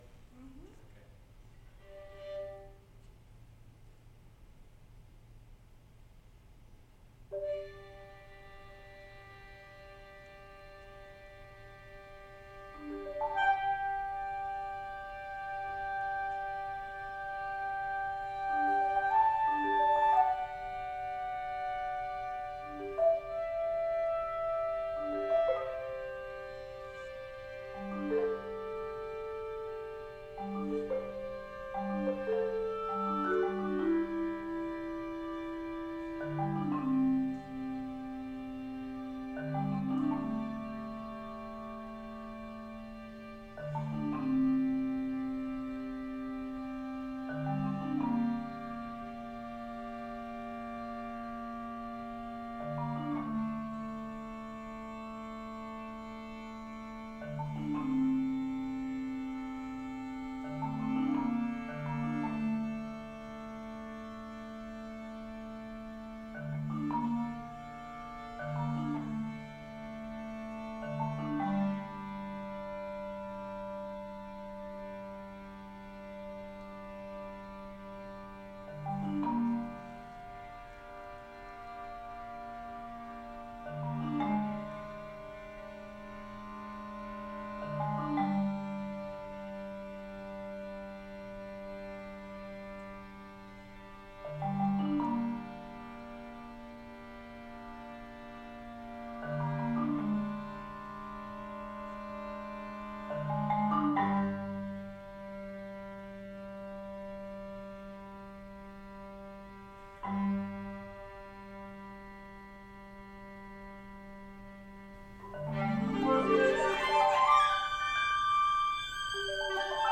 Clarinet, marimba, and violin trio In the Spring of 2019, I had the opportunity to participate in a Doctorate recital with the piece And Legions Will Rise by Kevin Putts.